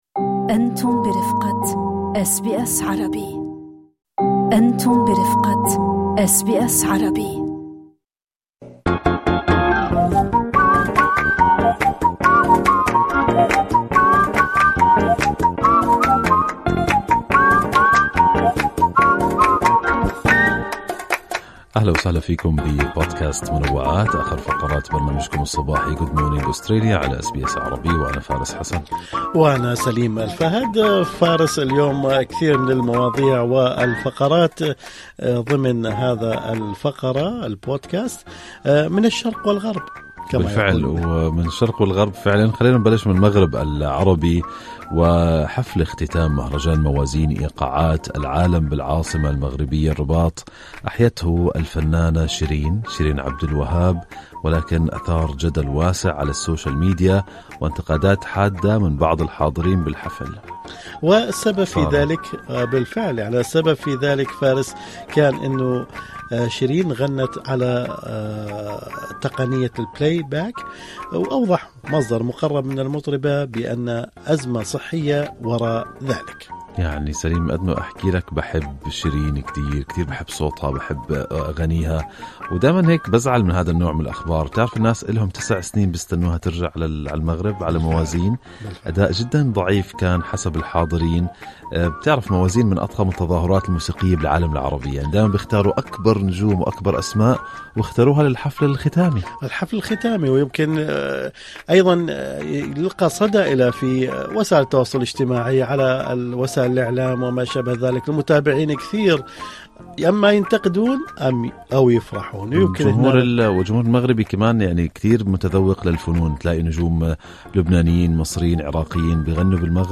نقدم لكم فقرة المنوعات من برنامج Good Morning Australia التي تحمل إليكم بعض الأخبار والمواضيع الأكثر رواجًا على مواقع التواصل الاجتماعي.